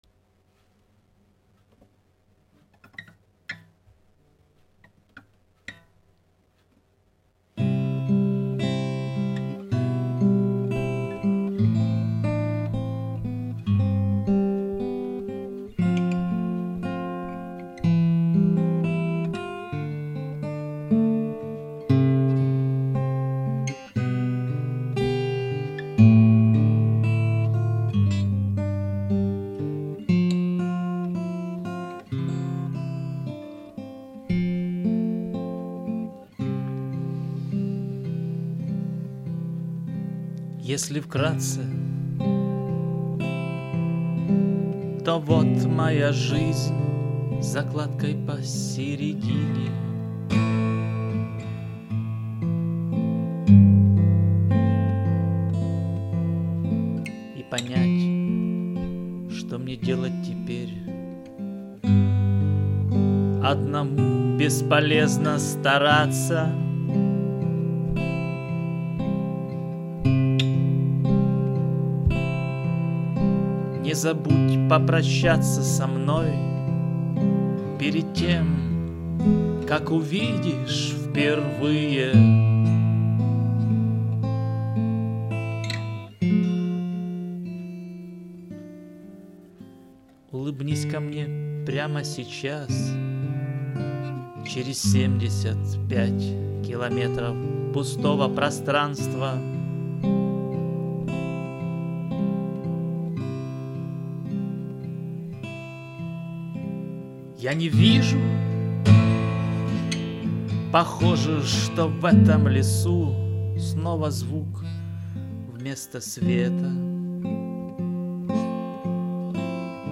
Гитаре повезло - заменил намедни ей струны через много лет.
Гитара кстати отлично звучит, 599!